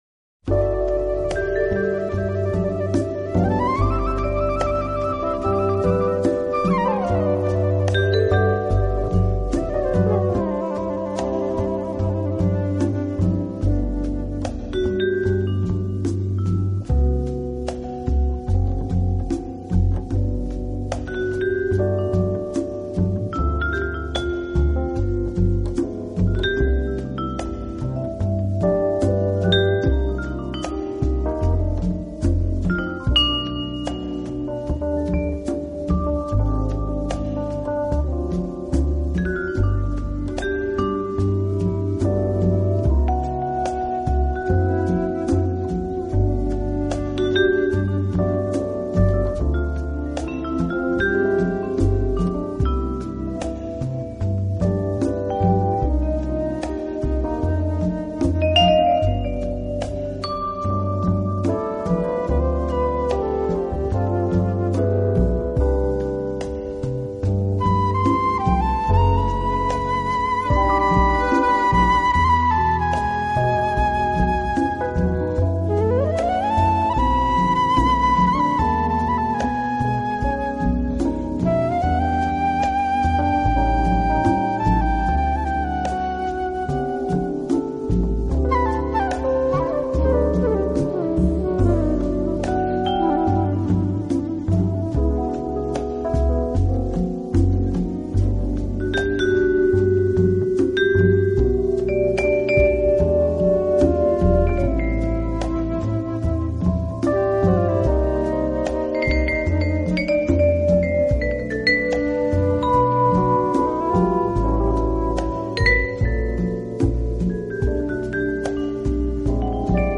【情调爵士】